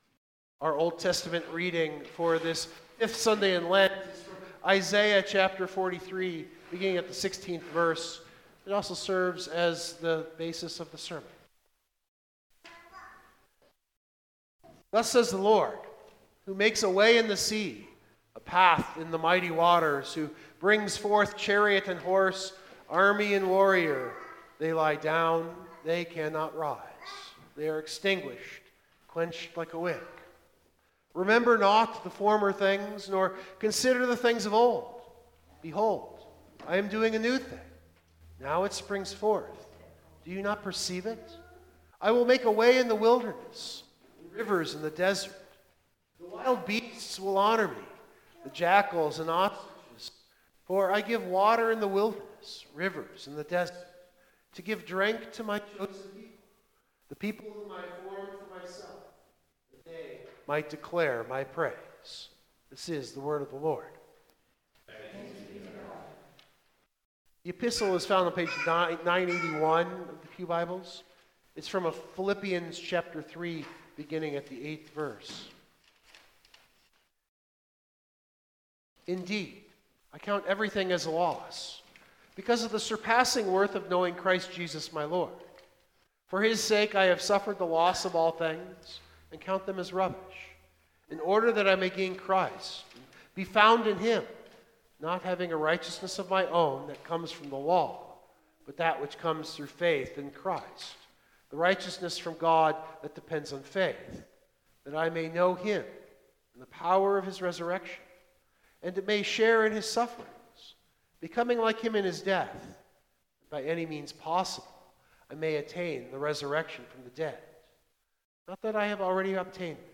Biblical Text: Luke 20:9-20 Full Sermon Draft
This is a re-recording after the fact, because the recording at the time something went wrong.